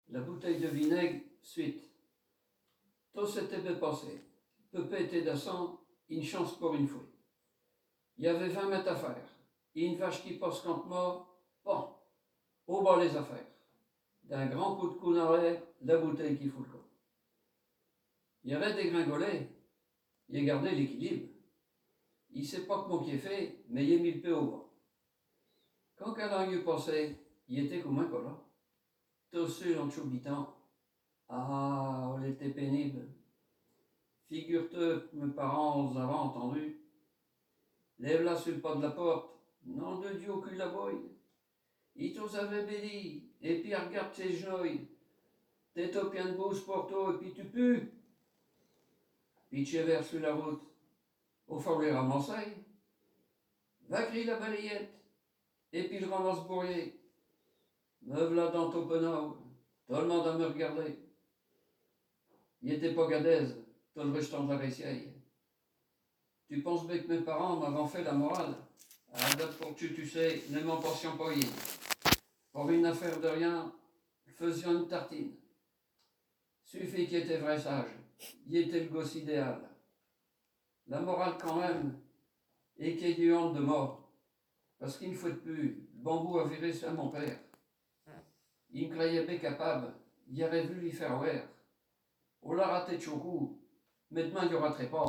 Genre poésie
Poésies en patois